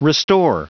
Prononciation du mot restore en anglais (fichier audio)
Prononciation du mot : restore